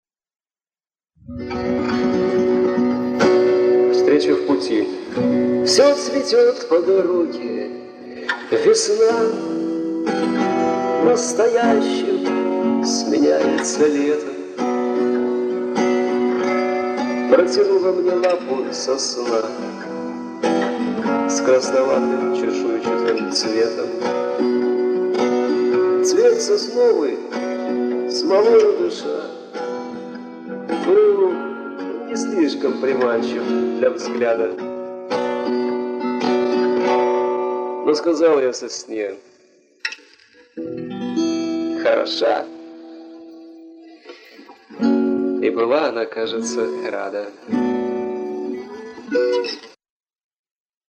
2003 г., Гомель, соло, по Маршаку - 02
Домашняя акустика
Качество записи - нормально